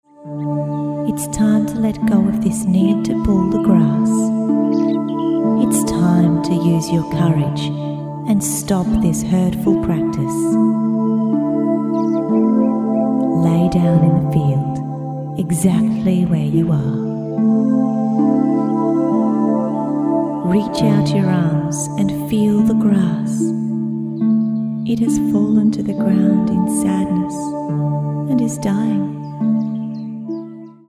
Trichotillomania (Hair Pulling) Self-Hypnosis